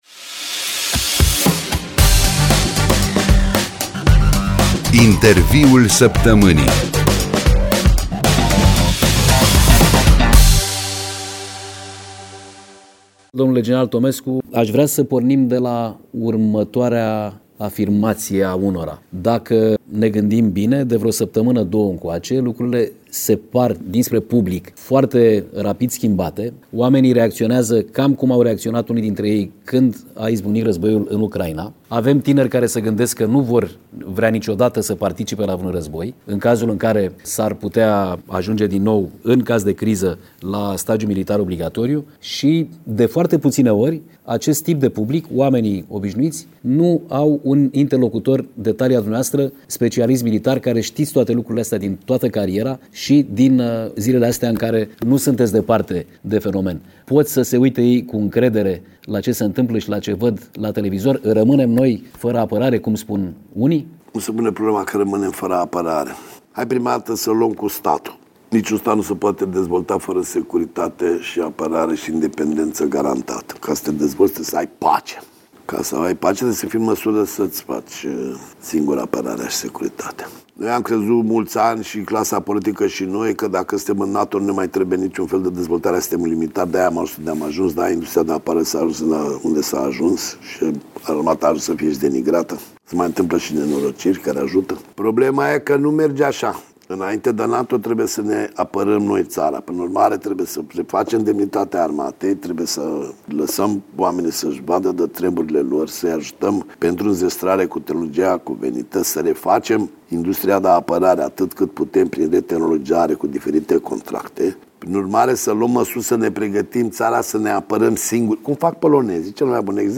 Interviul săptămânii